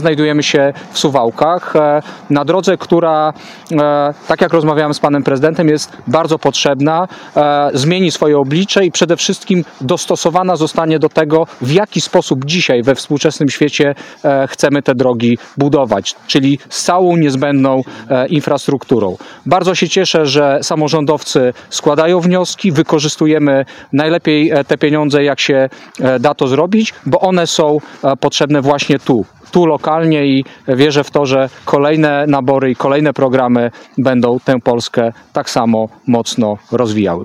Ponad 7 milionów złotych będzie kosztował remont ulicy Wylotowej w Suwałkach. Szczegóły przedstawili w sobotę (15.02.25) na konferencji prasowej Jacek Brzozowski, wojewoda podlaski i Czesław Renkiewicz, prezydent Suwałk.